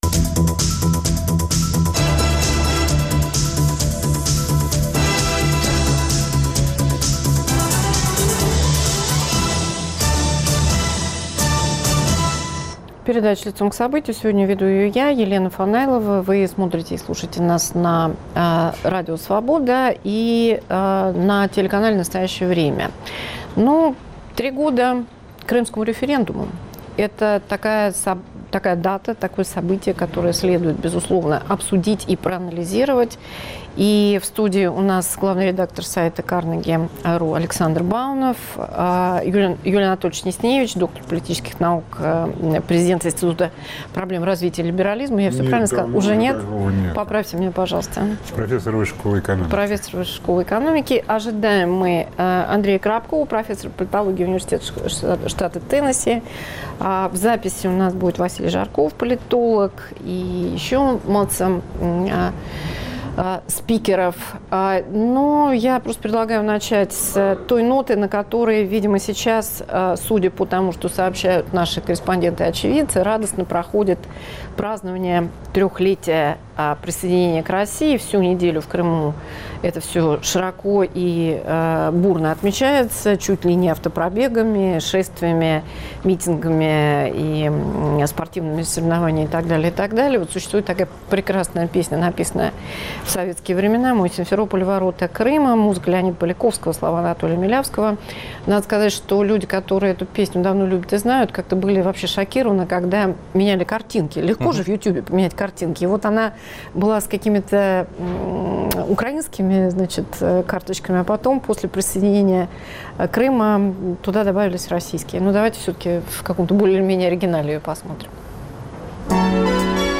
Приобретения и потери “крымской кампании” – к трехлетию референдума на полуострове. С политологами и журналистами